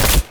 Sci-Fi Effects
impact_projectile_metal_007.wav